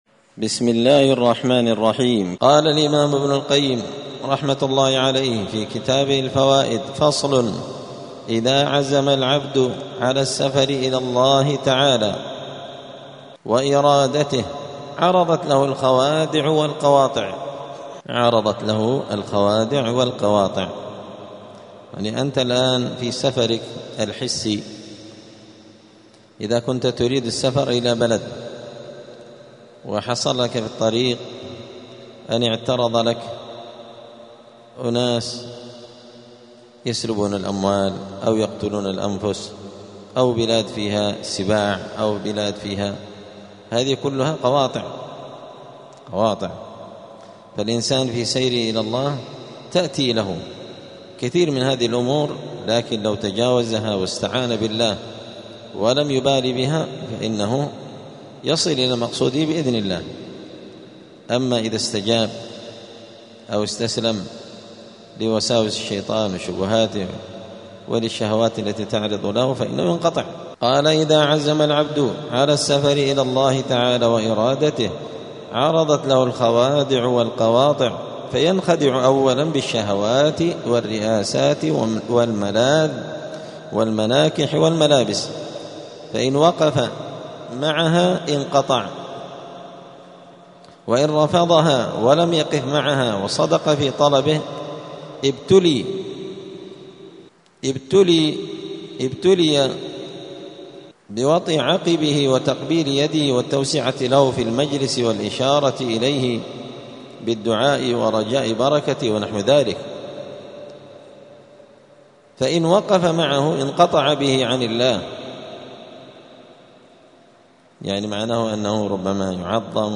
الأحد 29 ربيع الأول 1447 هــــ | الدروس، دروس الآداب، كتاب الفوائد للإمام ابن القيم رحمه الله | شارك بتعليقك | 22 المشاهدات